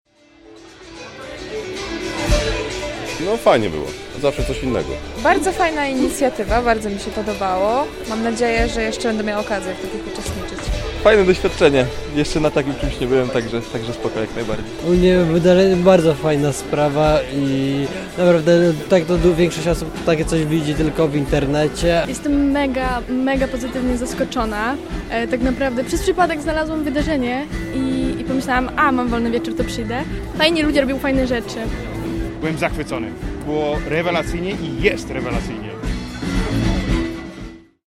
spytał uczestników o wrażenia
Całe wydarzenie miało miejsce w sobotę, 17 marca w Klubie 30.